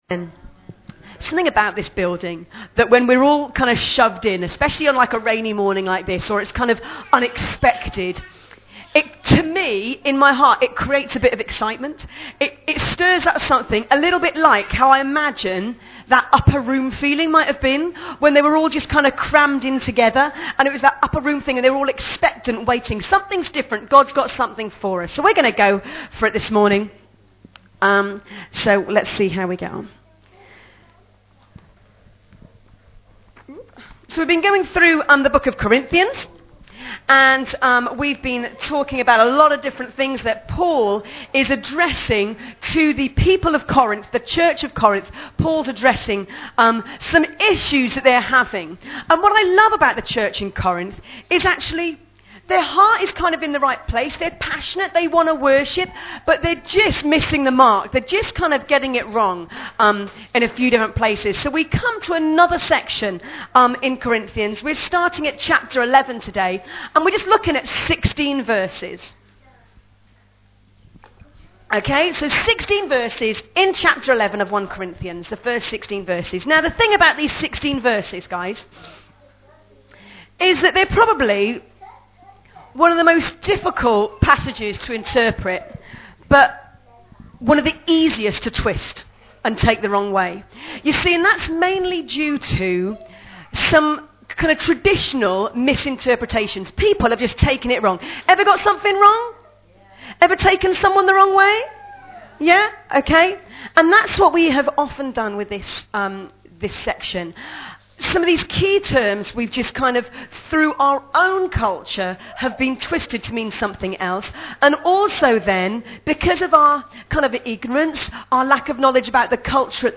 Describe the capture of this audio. Service Type: Celebration